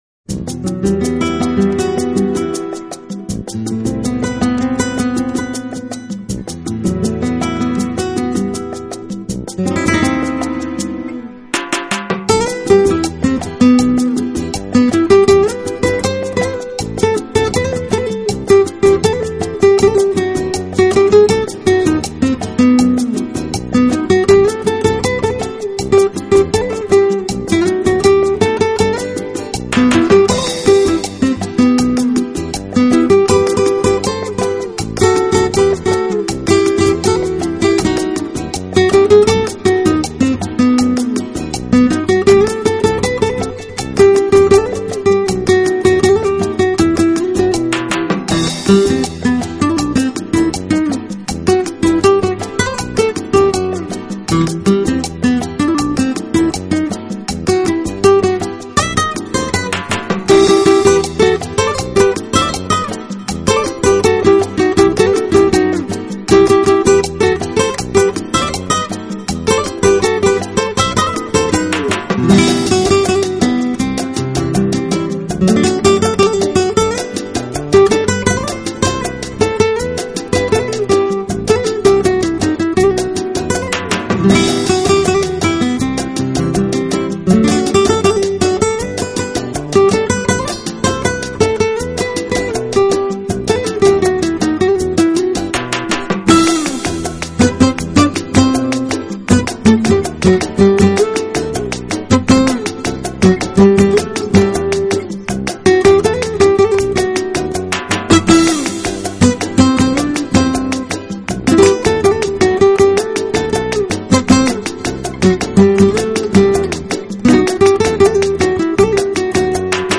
阳光，蕴涵令人跃然起舞翩翩的律动，音符中出所展现的生命热忱,正